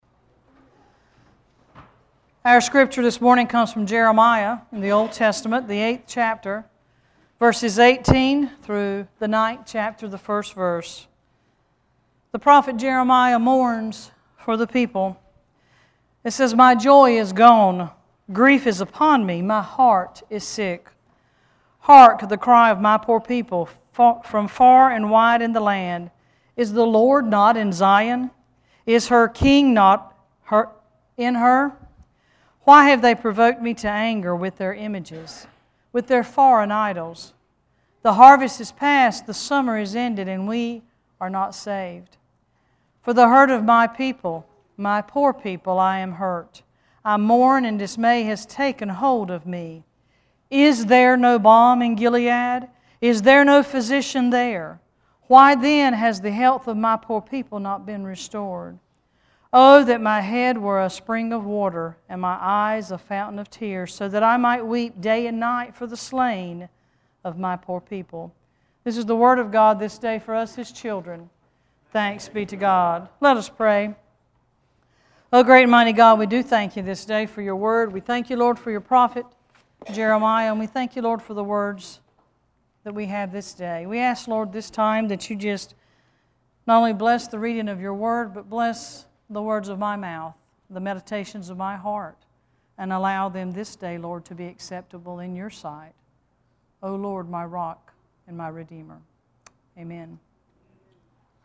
Worship Service 9-22-13: